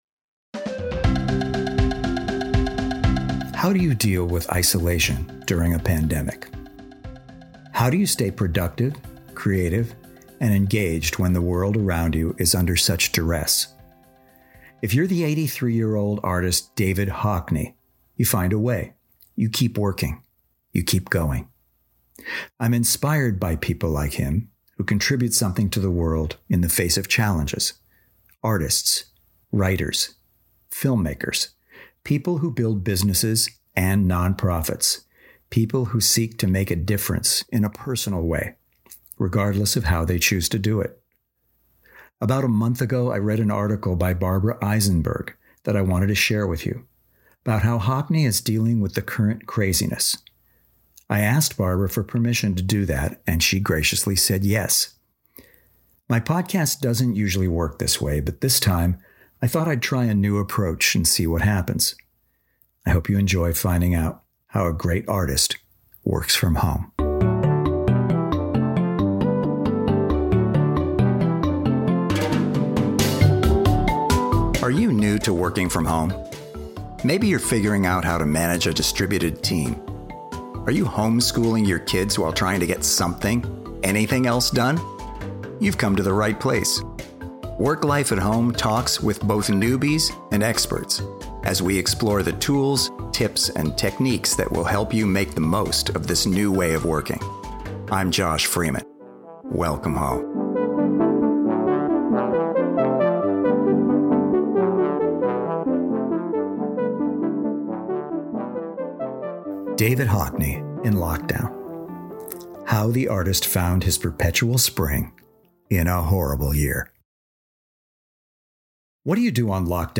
Music for the episode includes two beautiful piano variations